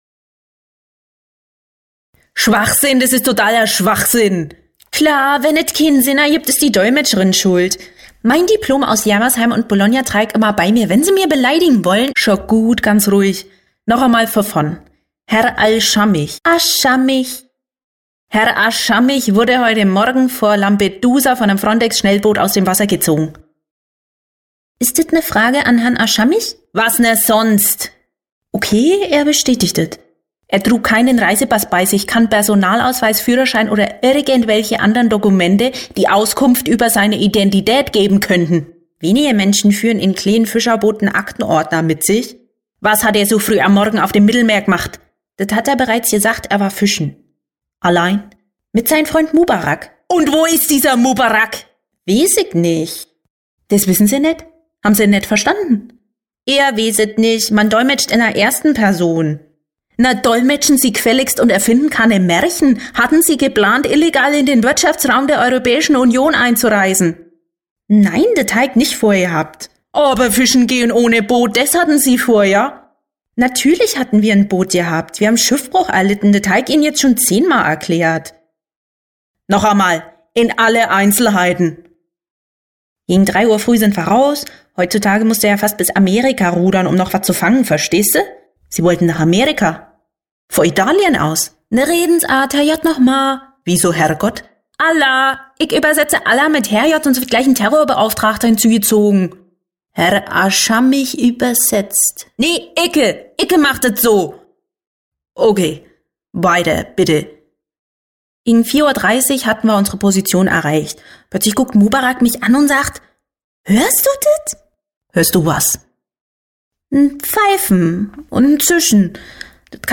Sprachproben
Female
Werbung Hörspiel Sachtext Prosa